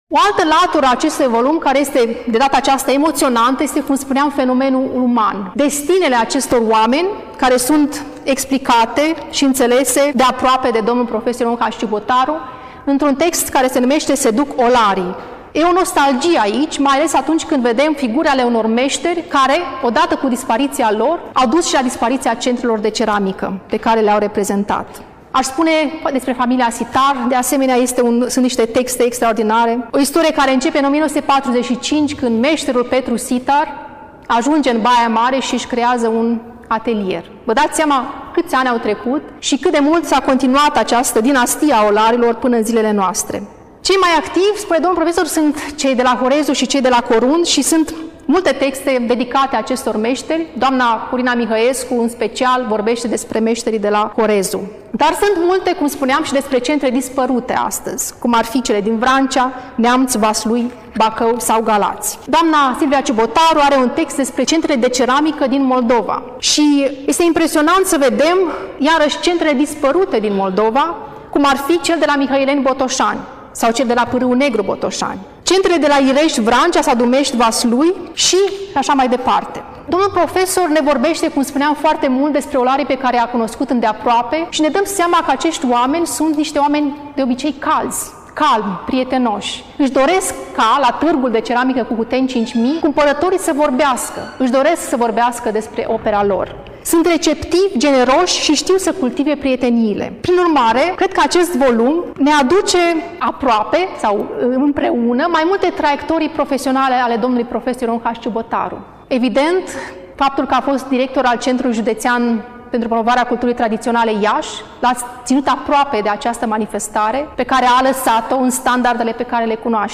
Până la final, difuzăm discursul